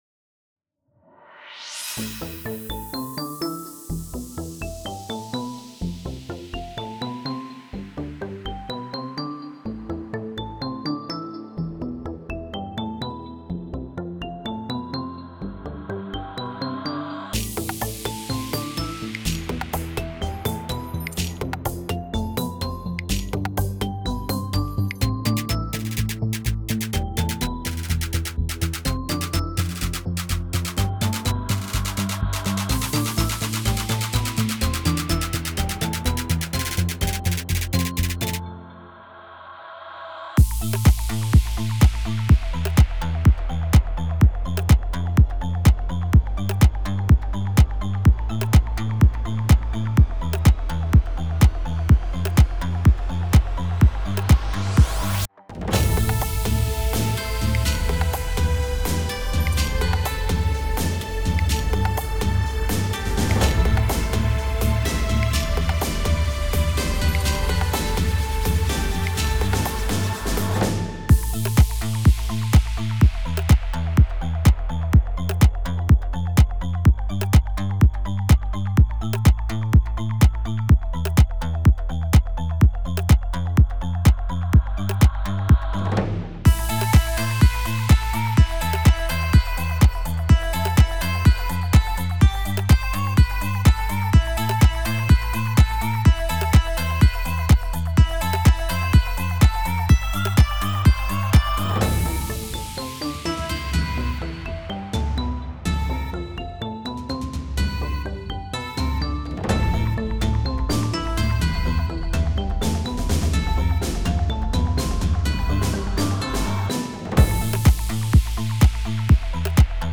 MUSIC INSTRUMENTALS - WAV FILES
Relaxing and Groovy